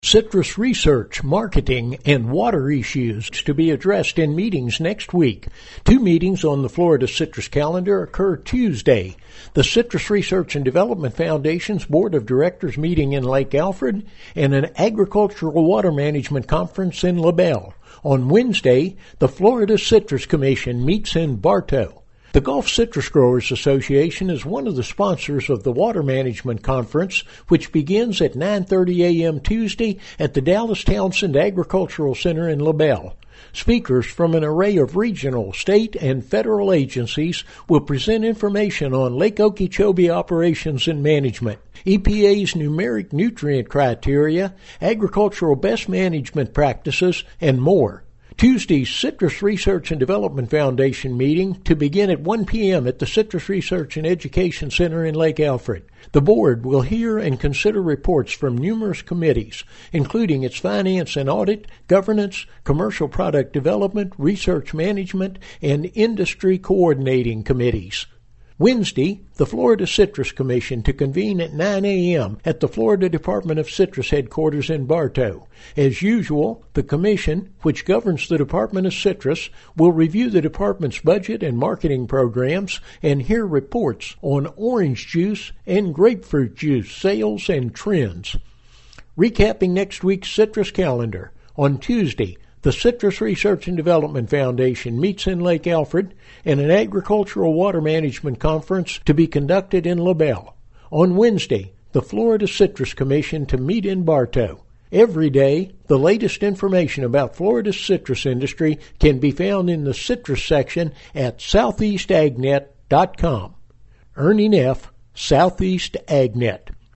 This report has details on three meetings next week of interest to the Florida citrus industry – the Citrus Research and Development Foundation’s Board of Directors meeting and an Agricultural Water Management Conference on Tuesday, and the Florida Citrus Commission meeting Wednesday.